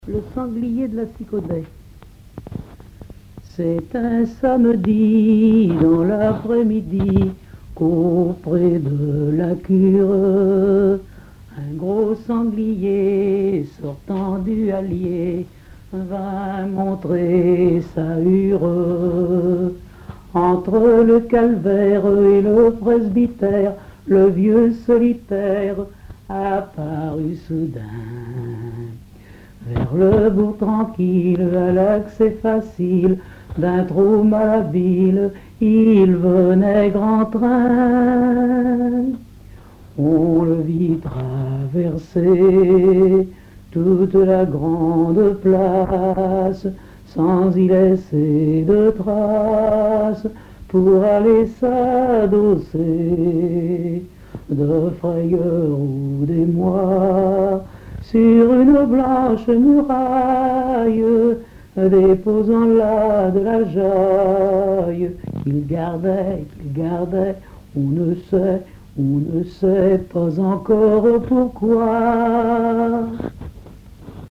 Récits et chansons en patois
Catégorie Pièce musicale inédite